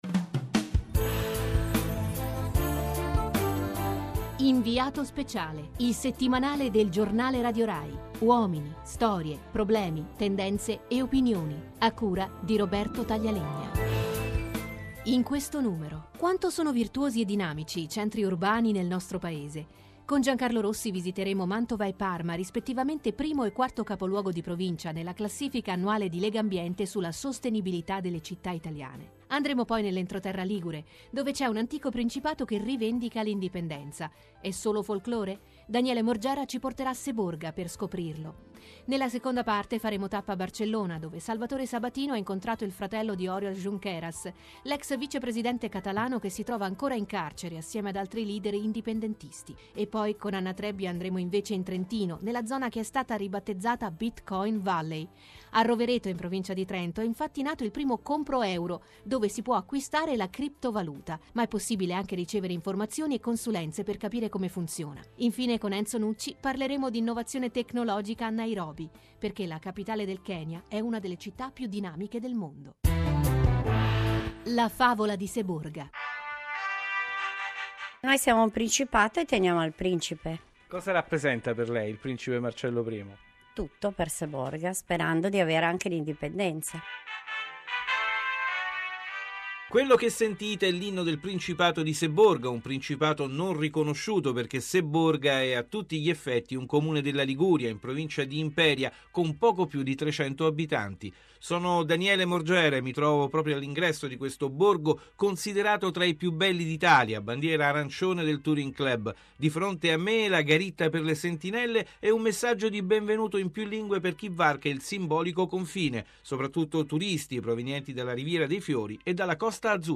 Questa mattina il programma “Inviato Speciale”, settimanale del giornale radio in onda su RAI Radio 1 alle 8.30, ha parlato del nostro Principato!